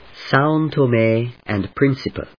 /sὰʊnṭəméɪən(d)prínsəpə(米国英語)/